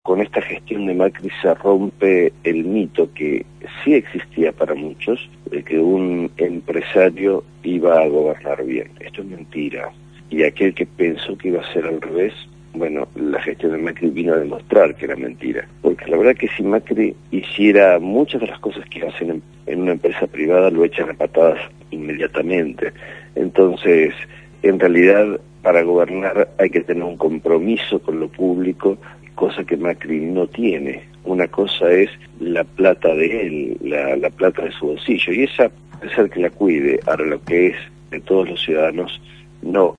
Lo dijo Aníbal Ibarra, Diputado de la Ciudad por el partido Diálogo por Buenos Aires, fue entrevistado en el programa «Punto de Partida» de Radio Gráfica